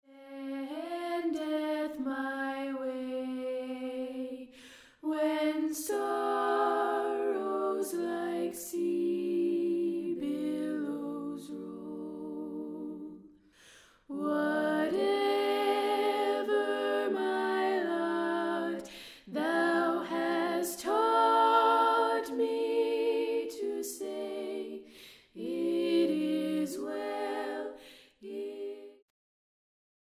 “When Peace like a River” – Women’s Trio
Womens-Trio-When-Peace-like-a-River.mp3